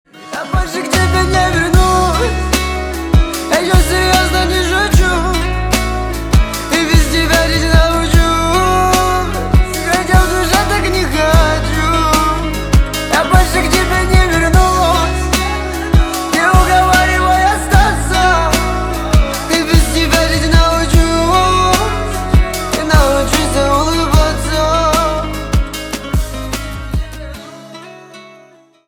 Поп Музыка # грустные # кавказские